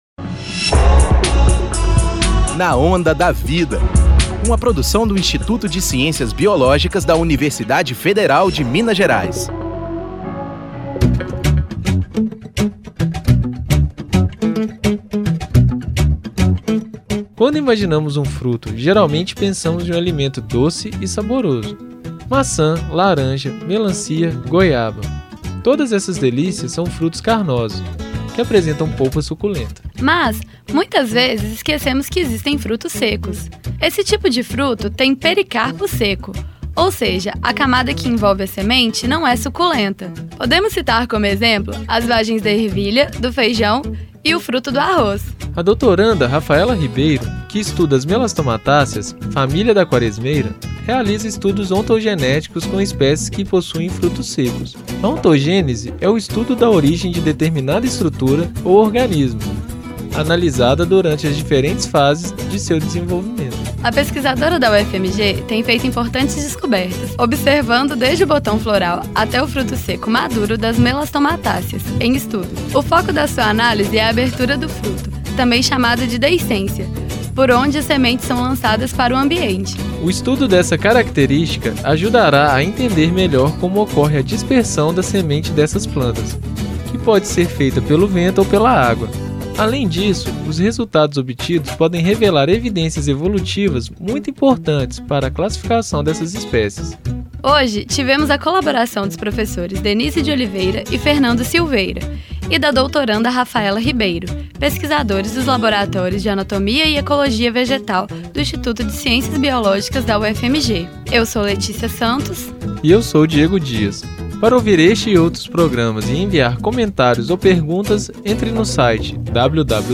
Vozes